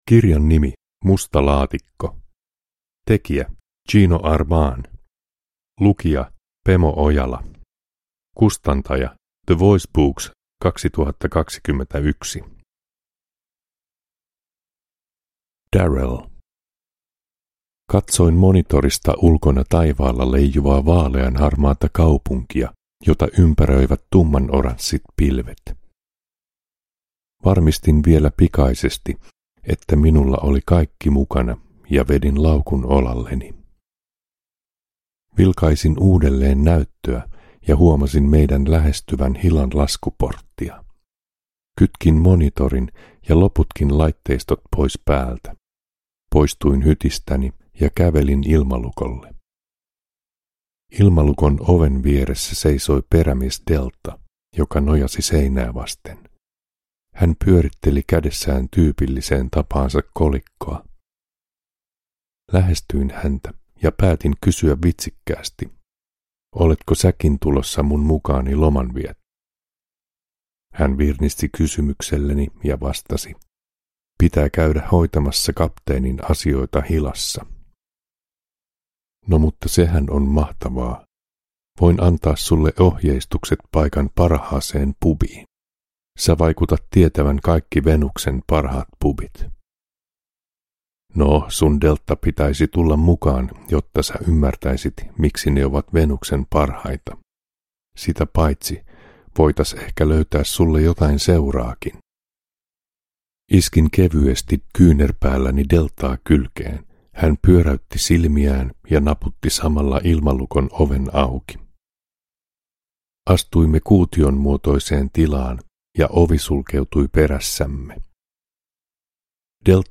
Musta Laatikko – Ljudbok – Laddas ner